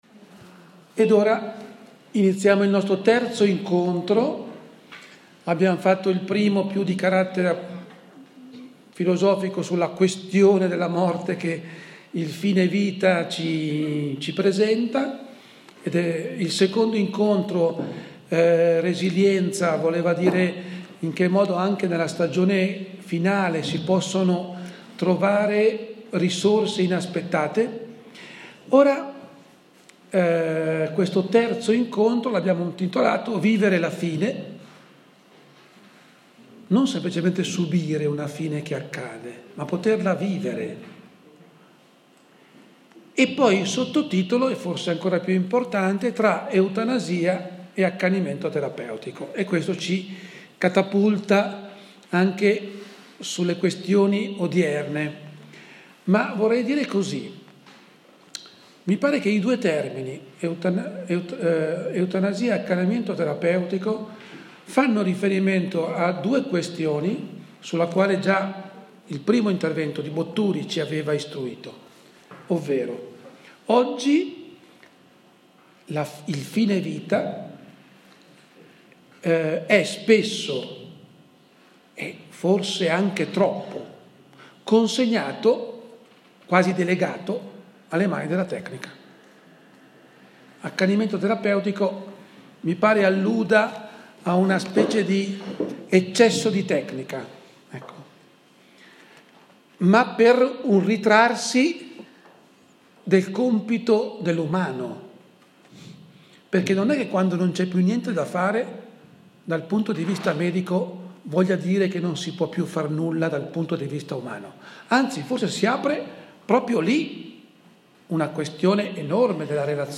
h. 21 - Salone Shalom, Parrocchia di San Vito al Giambellino
registrazione dell'incontro